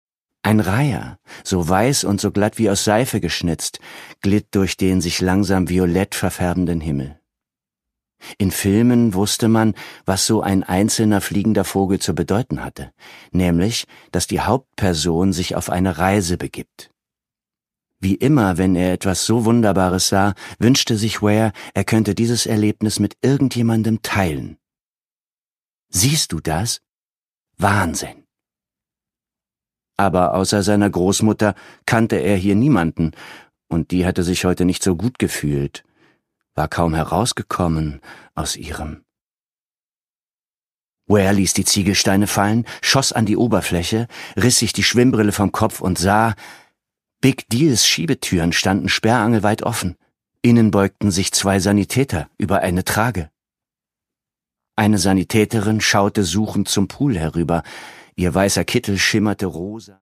Sara Pennypacker: Hier im echten Leben (Ungekürzte Lesung)
Produkttyp: Hörbuch-Download